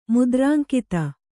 ♪ mudrānkita